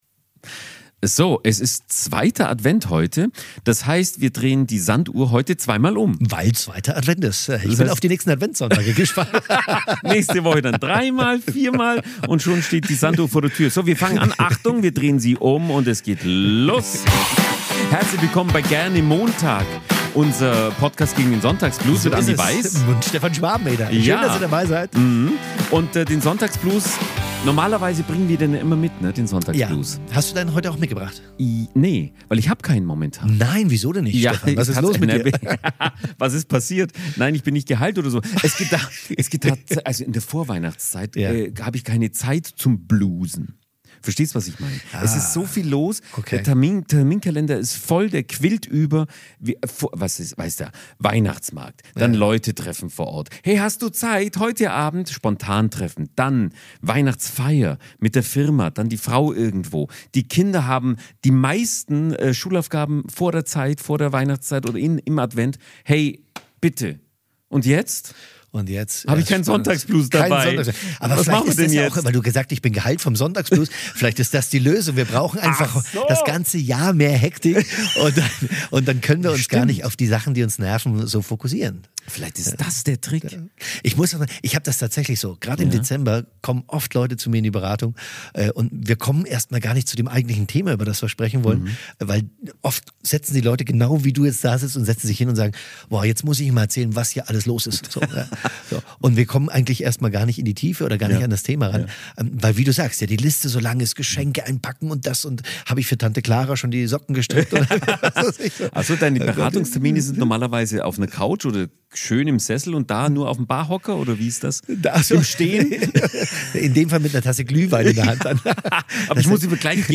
Unsere KI-Band „Die Montagsfreunde“ besingen, was uns Ruhe schenken kann.